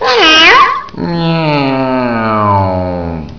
La impressió que donen els ulls d'un gat sempre ha sigut gran, encara que la seva veu sigui tan melosa com la que podreu escoltar si feu un clic a la fotografia de dalt.
miau.wav